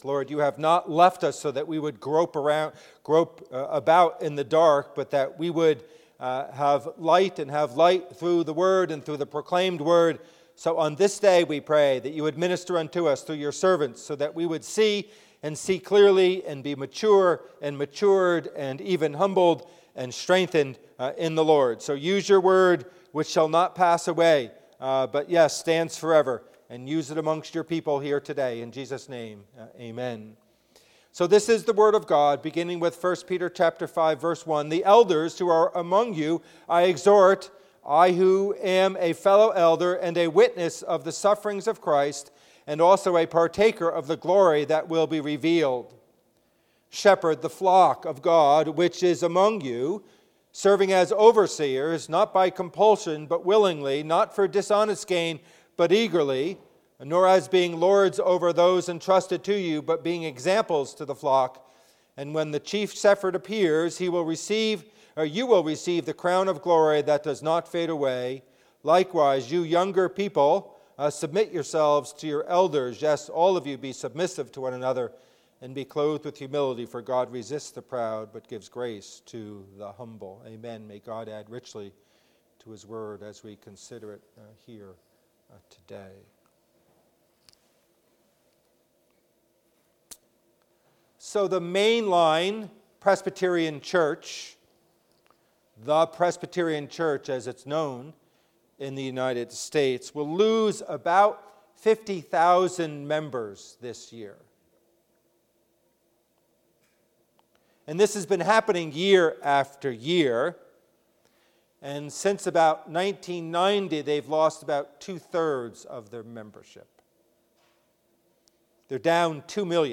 Service Type: Worship Service